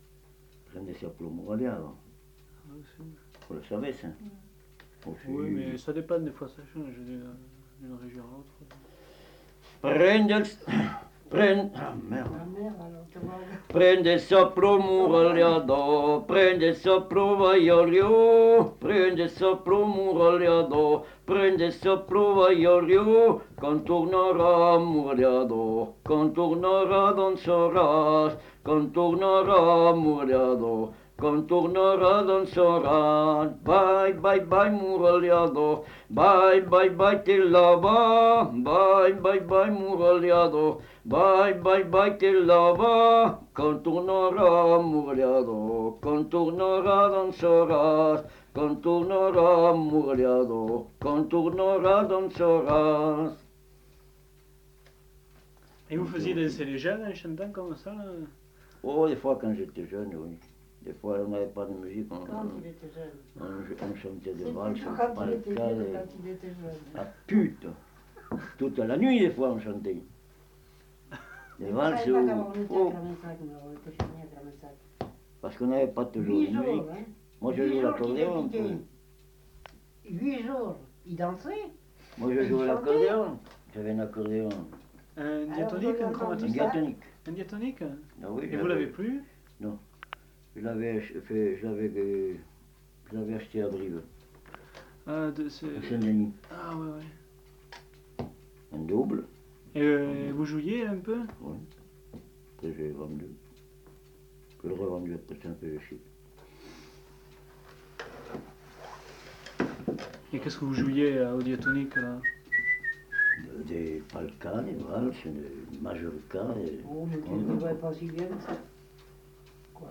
Aire culturelle : Viadène
Genre : chant
Effectif : 1
Type de voix : voix d'homme
Production du son : chanté
Danse : bourrée